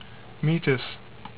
"MEE tis" ) is the innermost of Jupiter's known satellites: